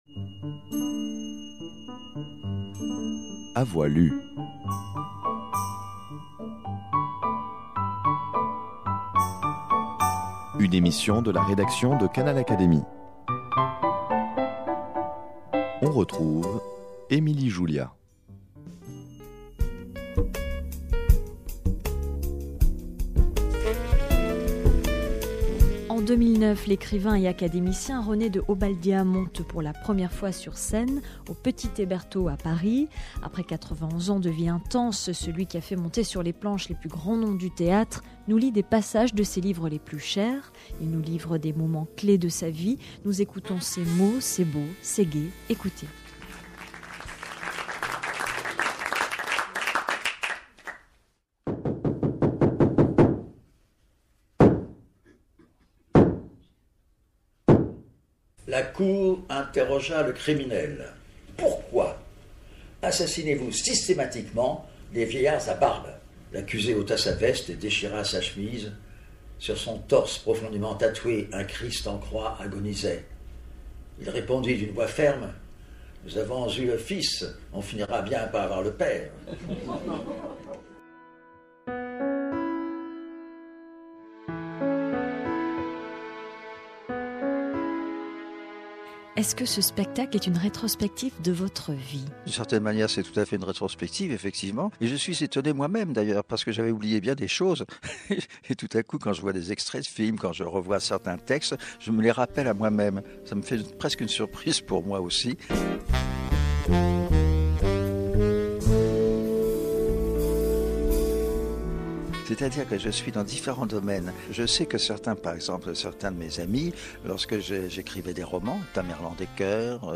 Canal Académie l’a enregistré sur scène et a recueilli ses émotions juste avant le lever de rideau.
Sur la scène du Petit Hébertot, à Paris, où il lit chaque soir des passages de son oeuvre, l'académicien René de Obaldia s'adonne à un exercice peu commode. Il vise à faire partager au public des extraits de livres et autres pièces de théâtre, selon son propre choix, avec humour et auto-dérision, simplicité et improvisation.
La magie du texte opère, d'une voix calme et assurée, l'auteur lit, parle, s'excuse « d'accuser son âge ».